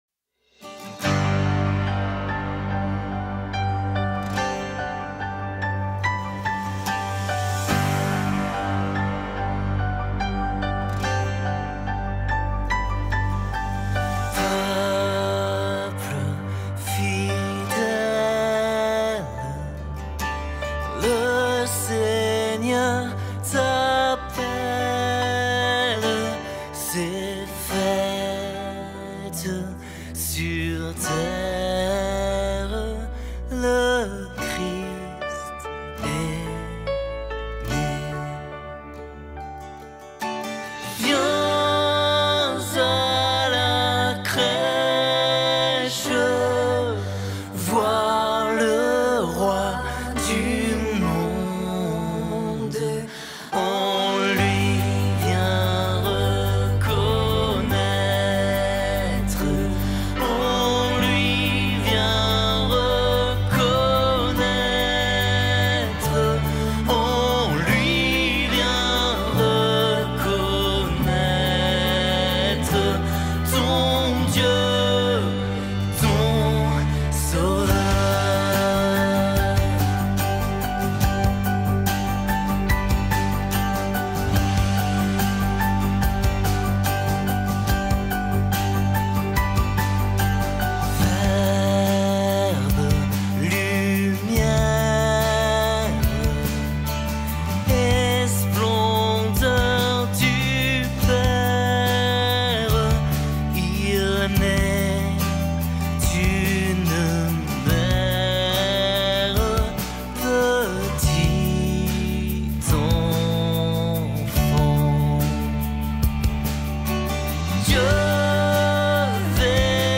46 просмотров 59 прослушиваний 0 скачиваний BPM: 115